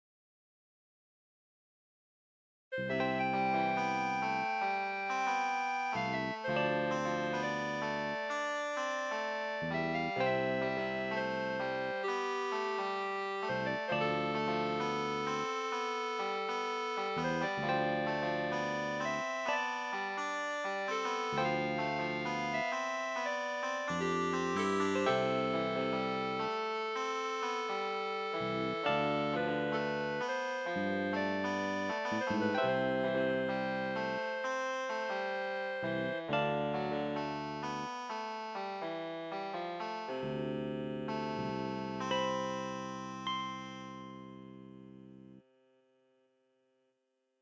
Simple little tune I made
lightheartedstyle_0.ogg